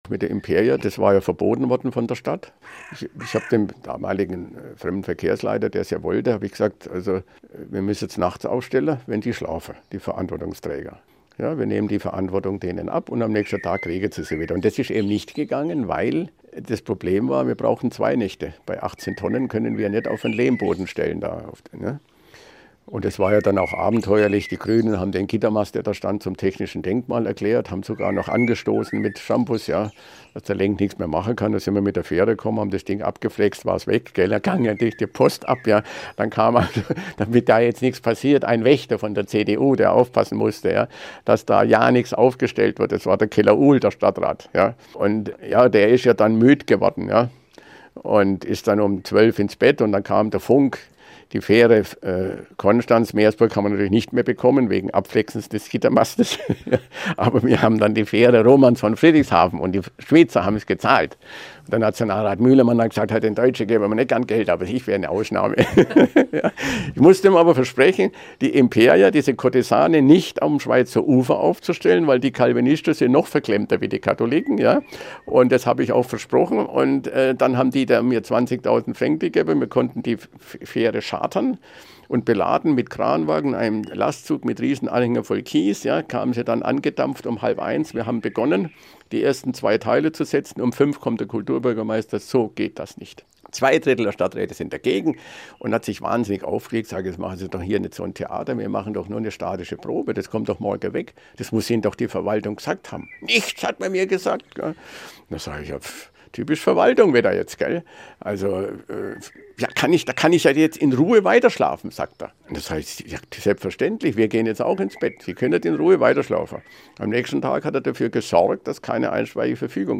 Anlässlich seines 75. Geburtstages im vergangenen Jahr sprach Lenk im SWR-Interview über die gewagte Aktion: